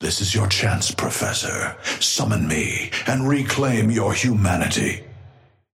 Amber Hand voice line - This is your chance, Professor.
Patron_male_ally_dynamo_start_01.mp3